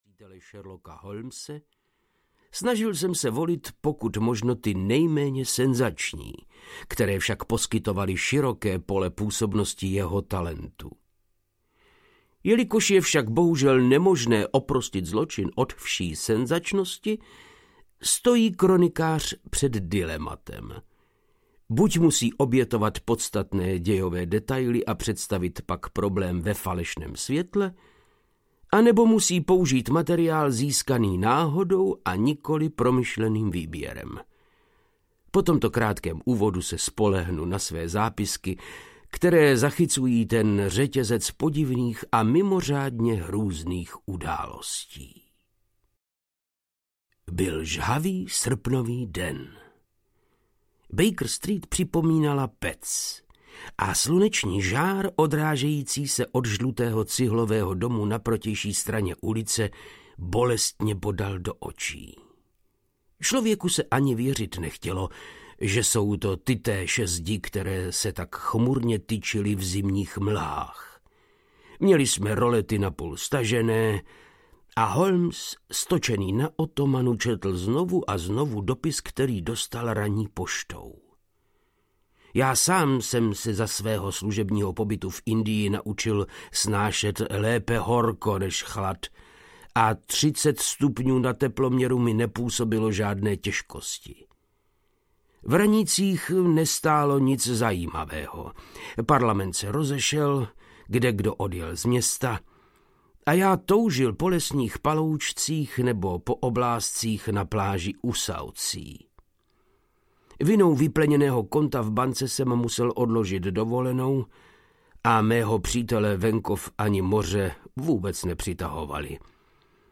Lepenková krabice audiokniha
Ukázka z knihy
• InterpretVáclav Knop